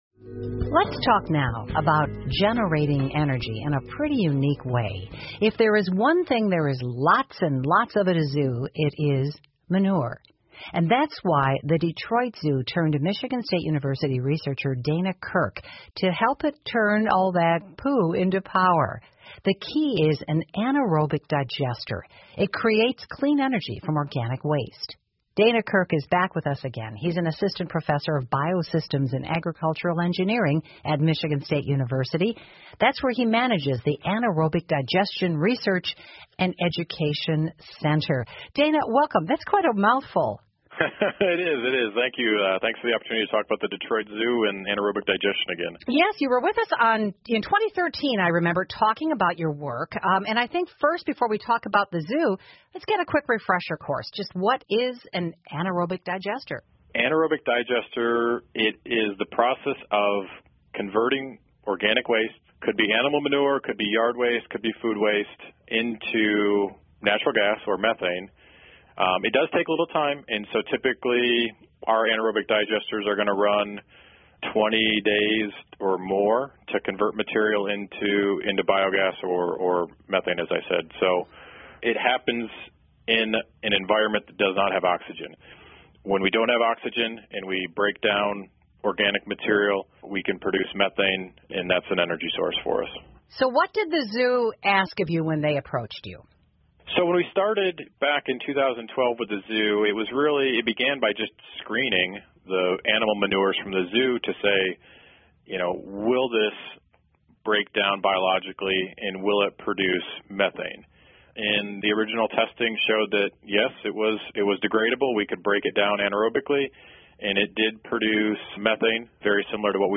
密歇根新闻广播 底特律动物园如何将粪便转化为清洁能源?